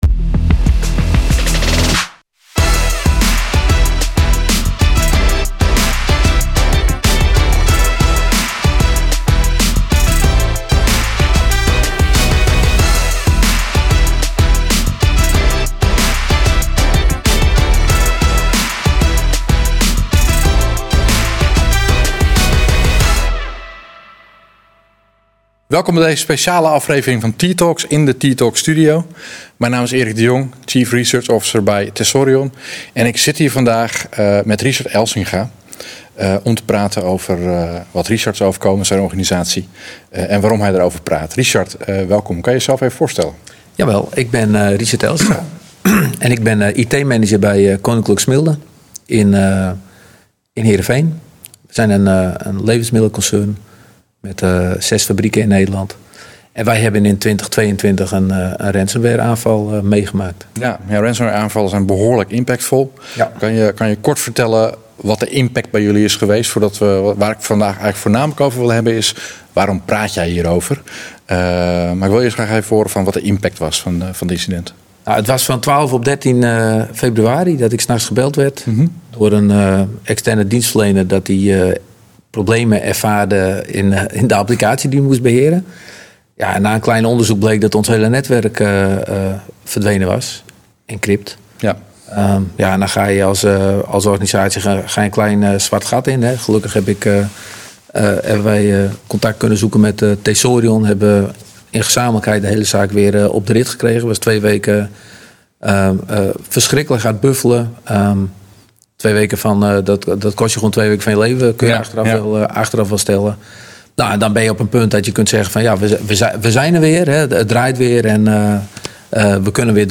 T-Talks is een serie van interactieve live online talkshows over actuele cyber security thema's, met experts uit het veld, die onder andere de vragen van kijkers behandelen. Deze podcast bestaat uit de opnames van deze live uitzendingen.